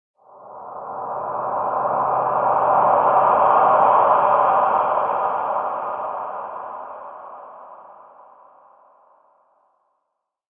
Eerie-airy-ahh-breath-sound-effect.mp3